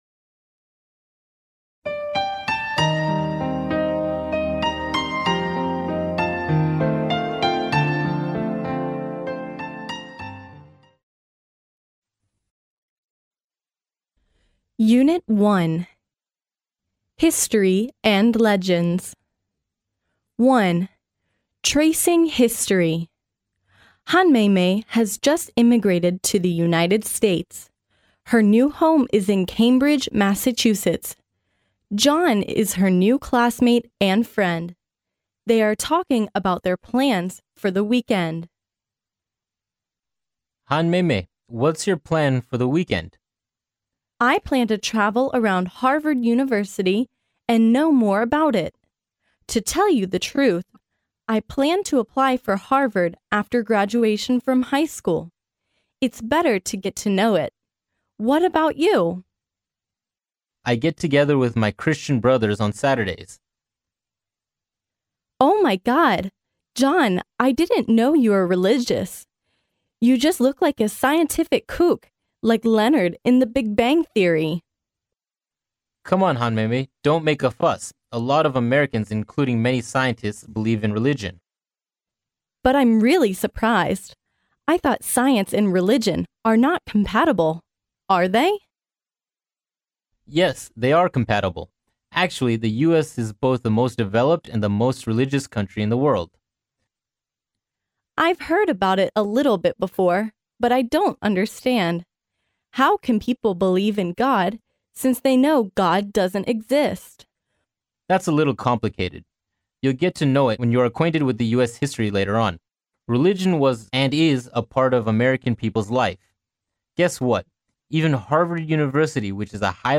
哈佛大学校园英语情景对话01：追溯哈佛历史（mp3+中英）